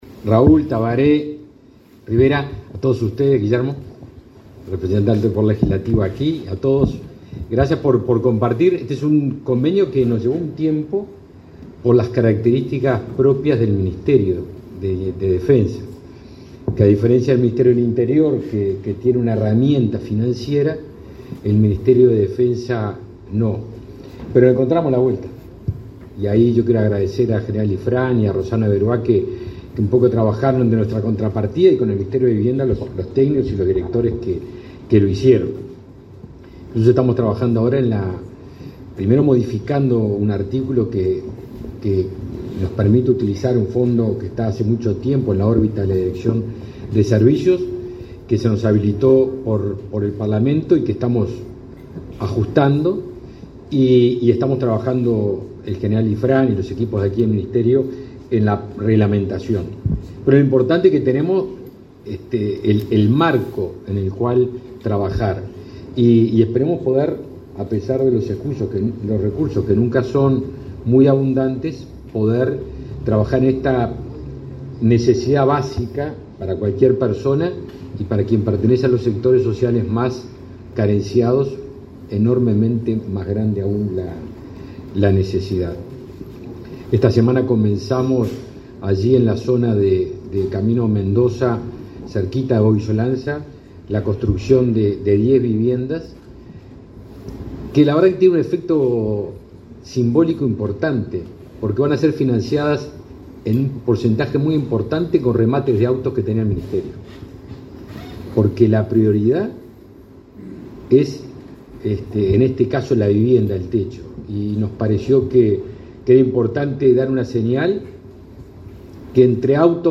Acto por la firma de convenio entre el MDN y el MVOT
El Ministerio de Defensa Nacional (MDN) y el Ministerio de Vivienda y Ordenamiento Territorial (MVOT) firmaron un acuerdo, este 18 de agosto, para que los funcionarios del MDN y los retirados, jubilados y pensionistas del Servicio de Retiros y Pensiones de las Fuerzas Armadas accedan a soluciones habitacionales. En el acto participaron los ministros Javier García y Raúl Lozano.